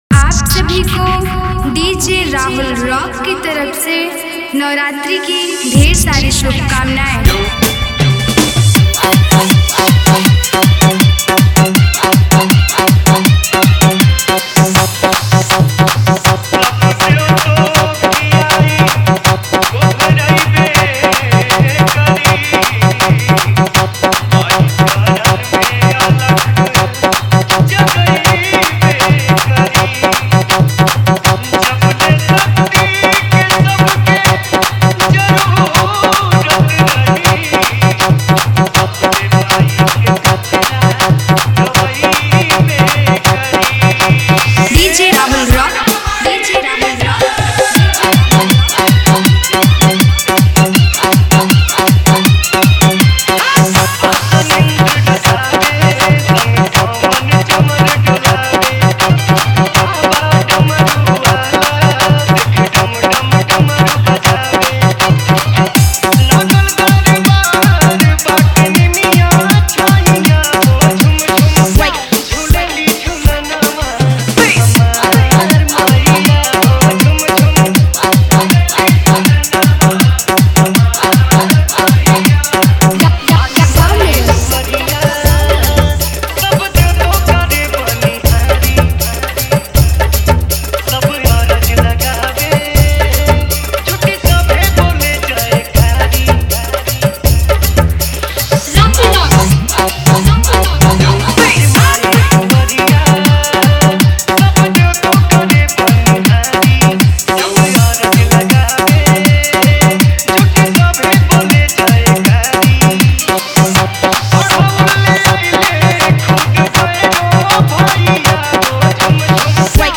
All Bhakti Dj Remix Songs